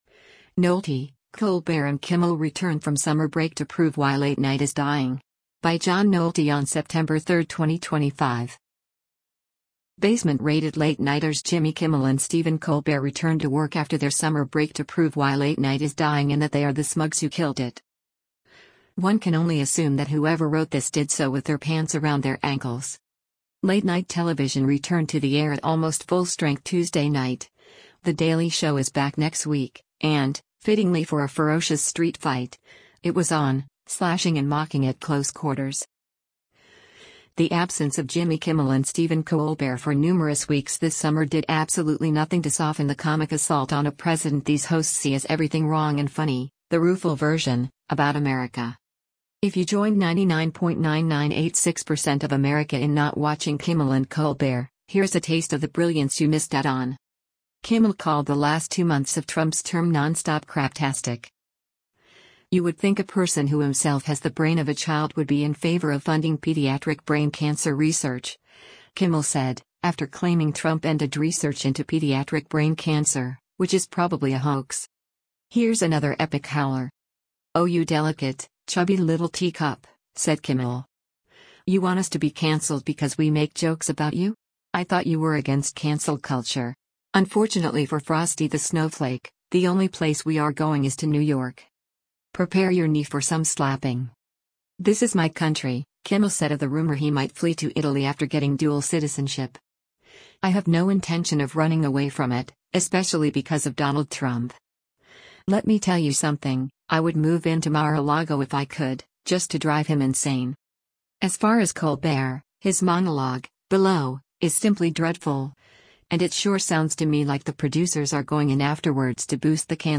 As far as Colbert, his monologue (below) is simply dreadful, and it sure sounds to me like the producers are going in afterwards to boost the canned laughter….